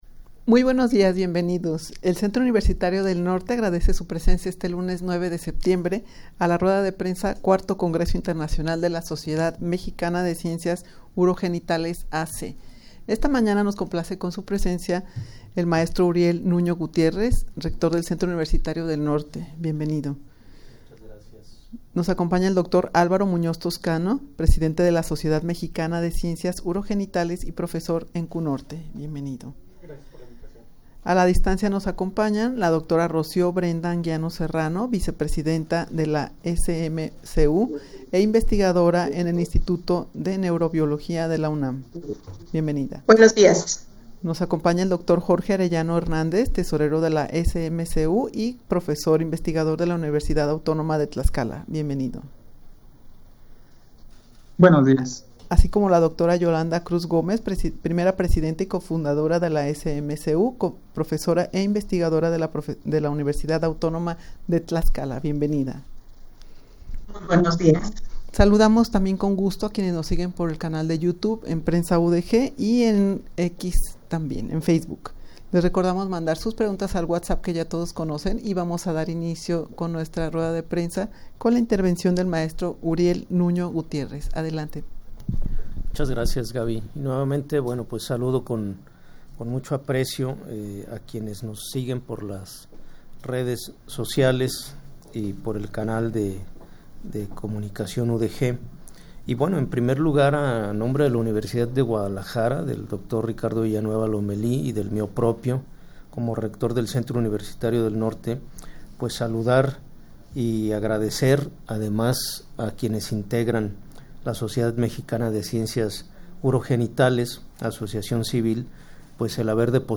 Audio de la Rueda de Prensa
rueda-de-prensa-iv-congreso-internacional-de-la-sociedad-mexicana-de-ciencias-urogenitales-a.c.mp3